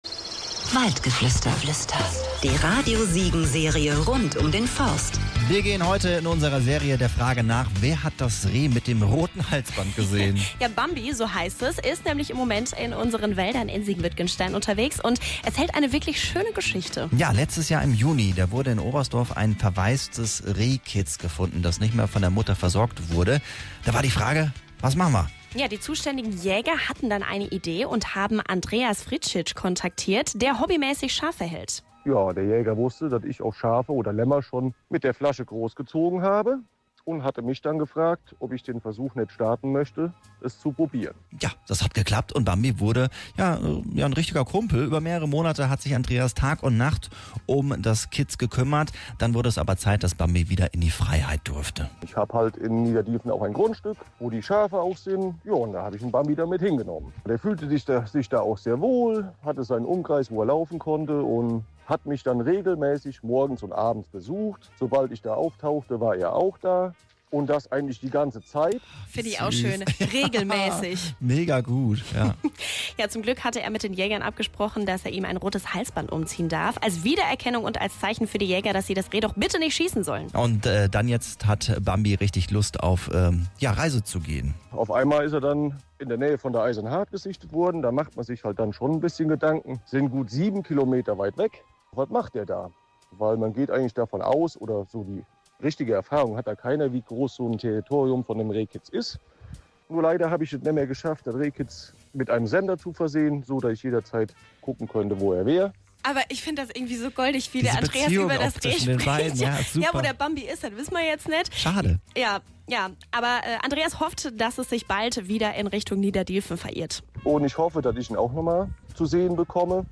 auf einen Spaziergang durch den Wald